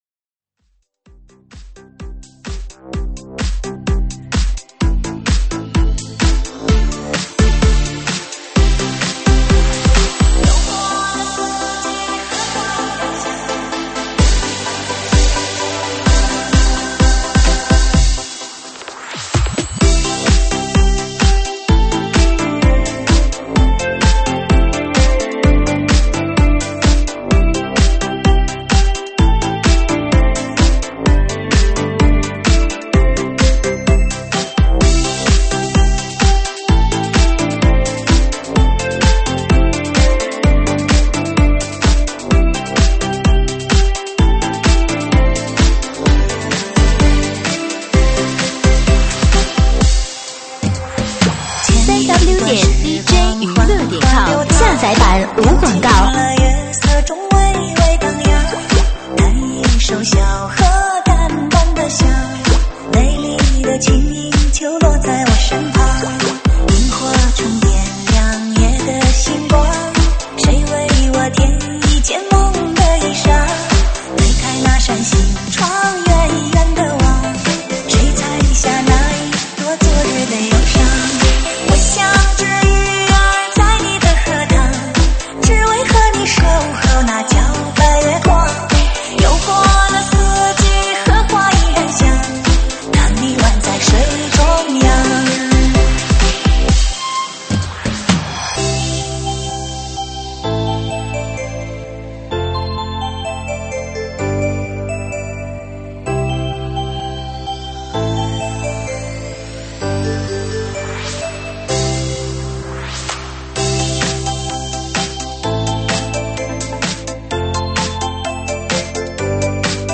(现场串烧)